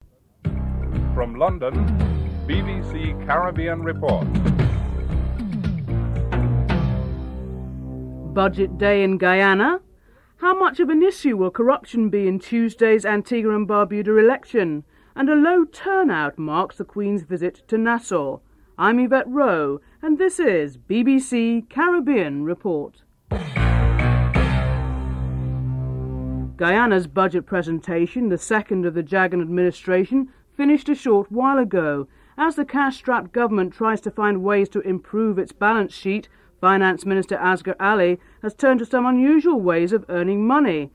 9. Wrap up and theme music (14:50-15:06)